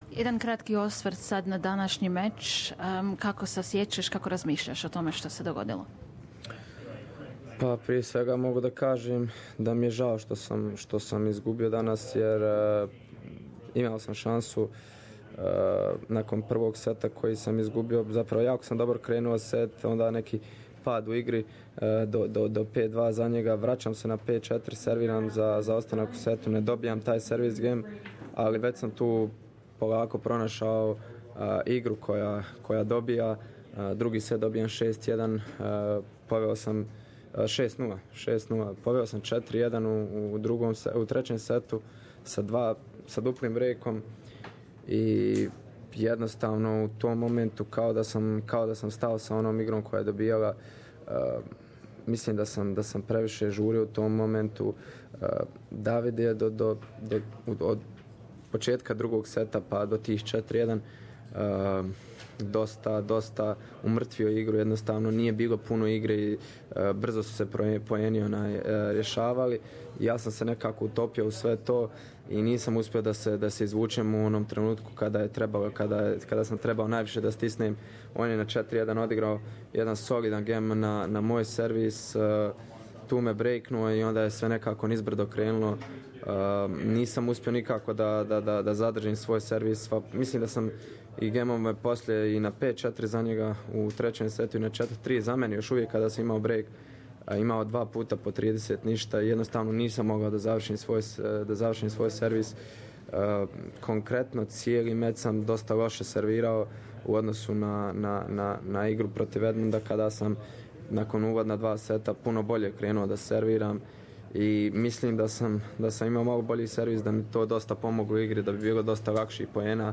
Australian Open 2016